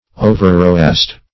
Overroast \O`ver*roast"\, v. t. To roast too much.